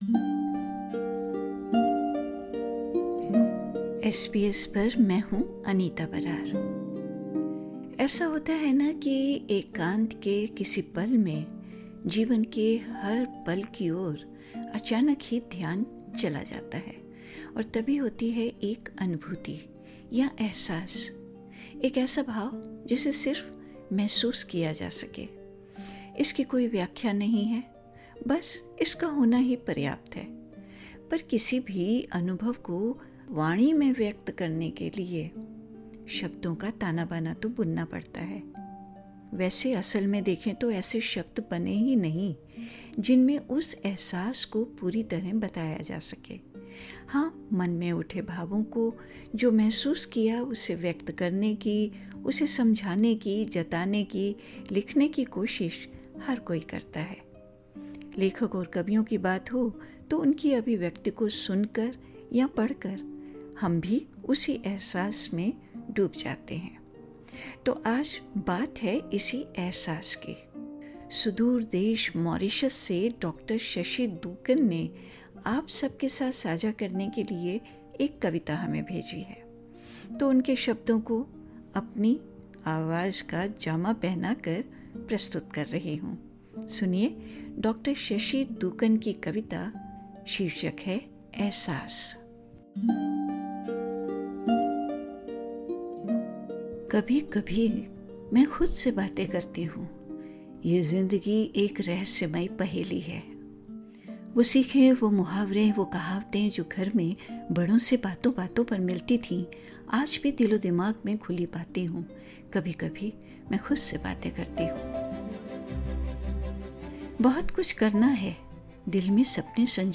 कविता 'एहसास' - कभी-कभी मैं ख़ुद से बातें करती हूँ